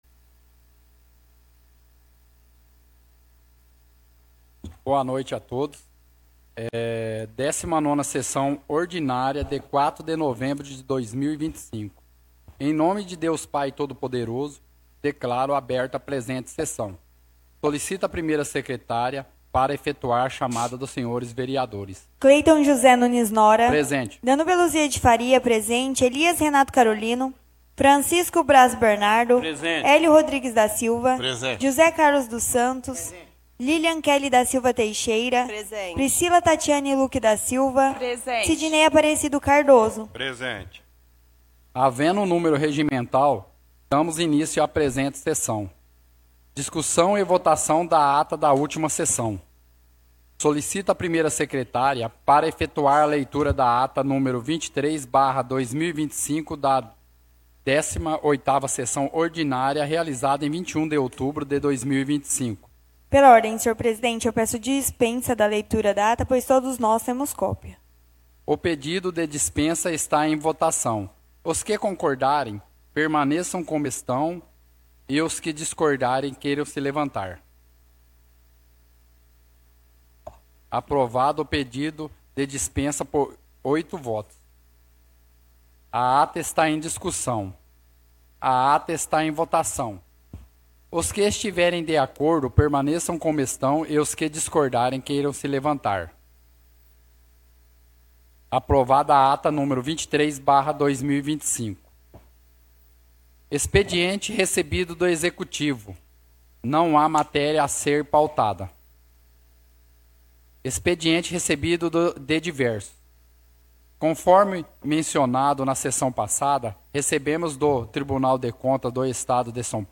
Áudio da 19ª Sessão Ordinária – 04/11/2025